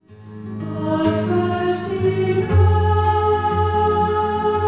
Traditional British.
12-string guitar
soprano sax